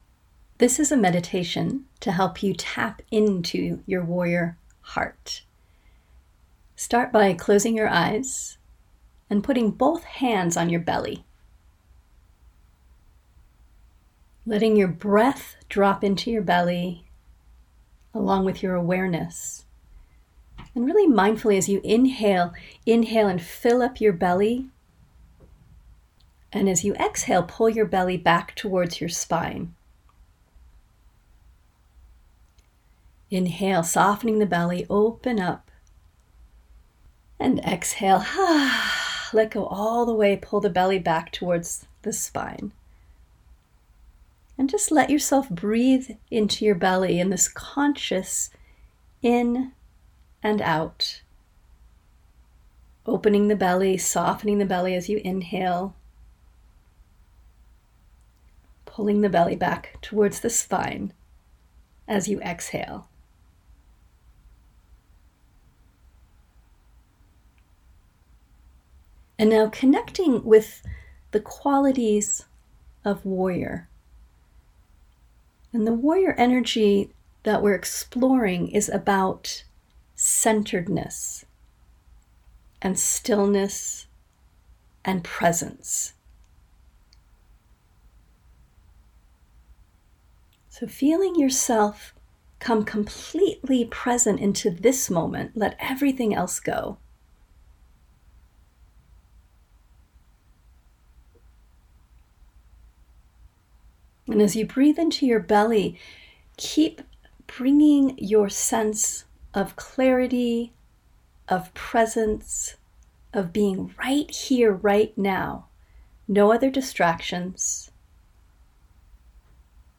WHG_Meditation_#1_Warrior_Heart.mp3